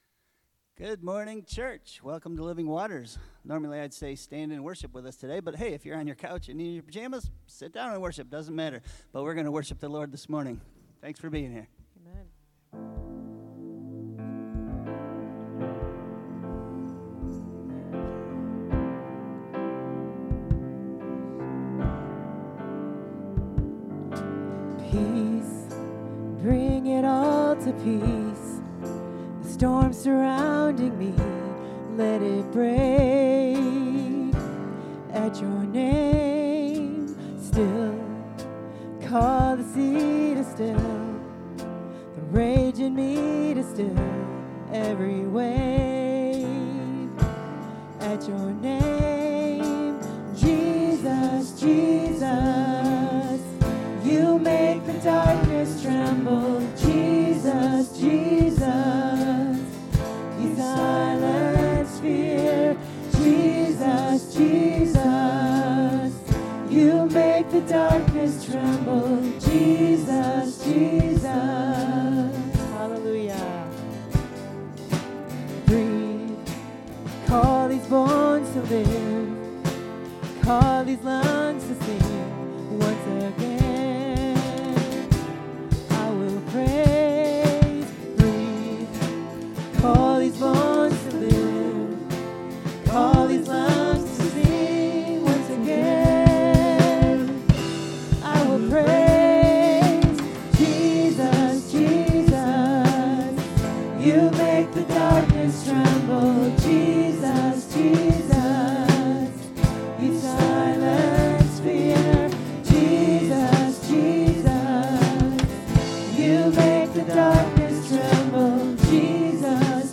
A message from the series "Know Him By Name."